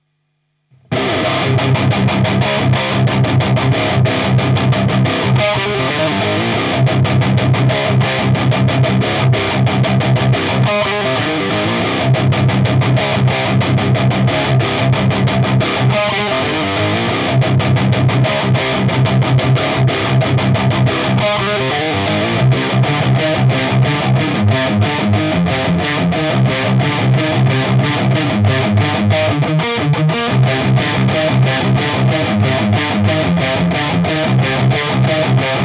Voici 4 sample de chaque canal en Vintage/Modern.
Le Master est compensé sur les 2 canaux car le Red sonne moins fort le Orange.